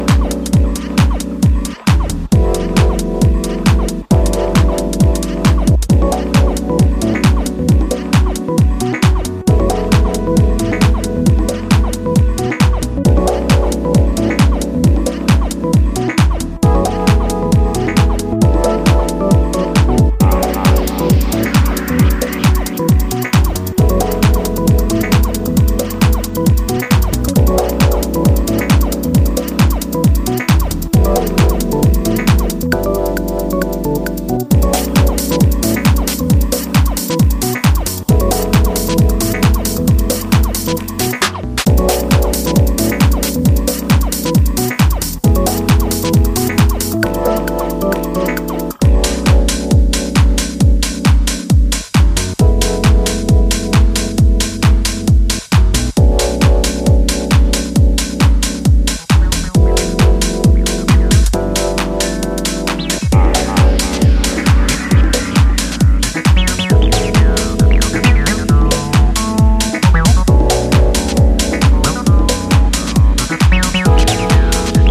funk-fuelled and deep dive into the early morning hours